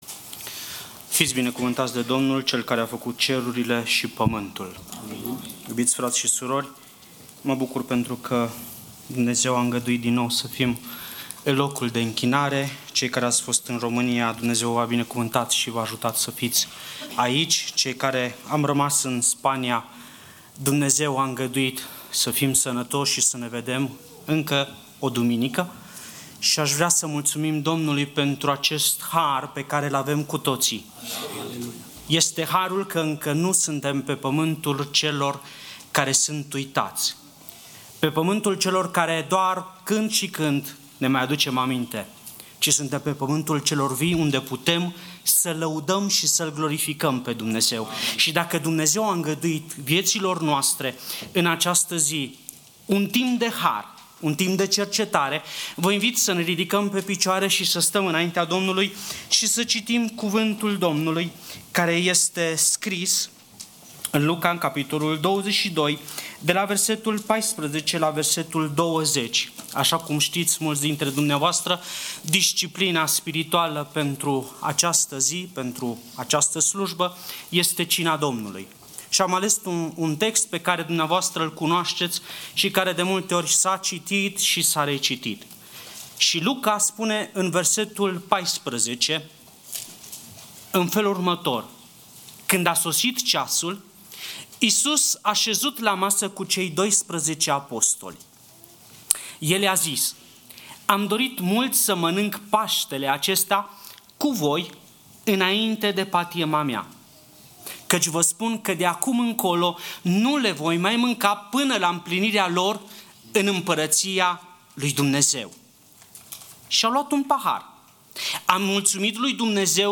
Series: Disciplinele Spirituale Passage: Luca 22:14-20 Tipul Slujbei: Slujba Dimineata